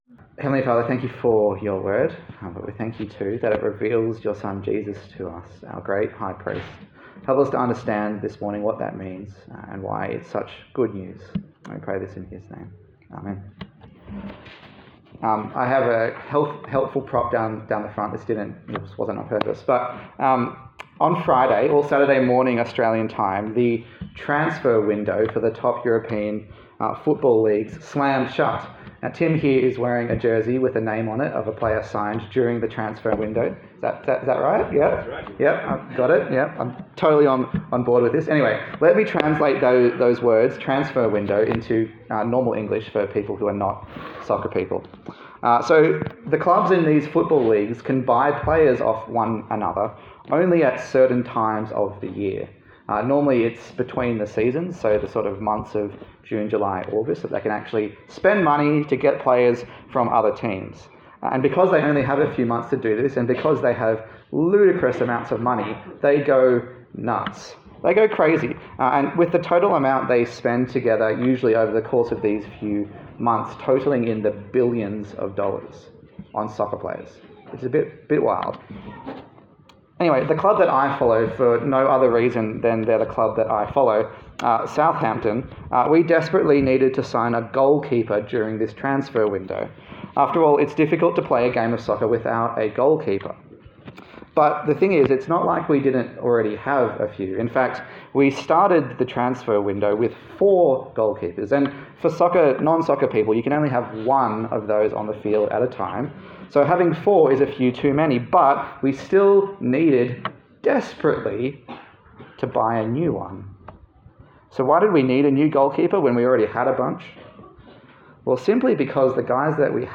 A sermon in the series on the letter to the Hebrews
Hebrews Passage: Hebrews 7 Service Type: Sunday Service